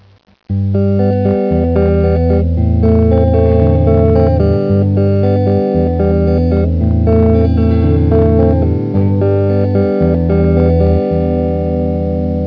:o) All of the pieces are just little bits of intros to songs, small finger picking pieces since I can't do anything else yet, I need to spend some more time on chords and stuff.